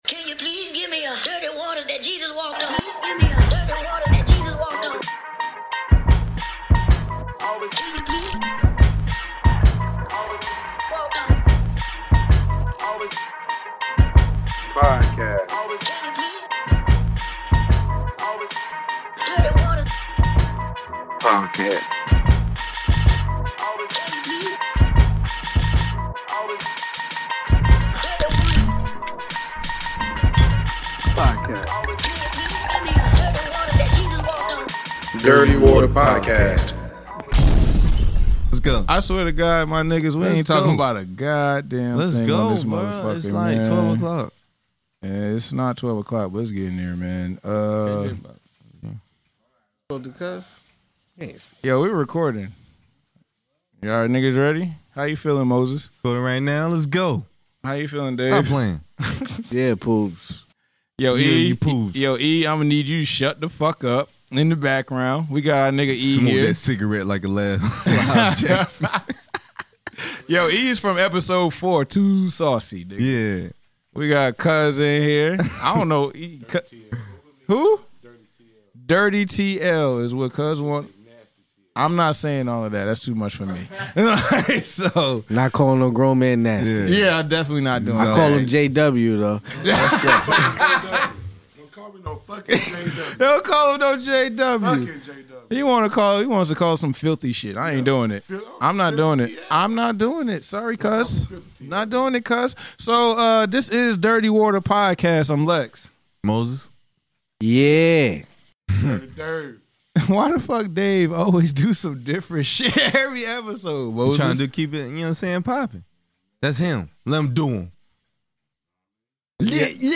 On this episode, the guys sit down to discuss Dreamville going number one, then they get into JP Morgan moving weight out of Philly, armor truck in Atlanta and many more!!